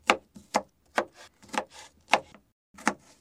Звуки моркови
8. Морковь измельчают ножом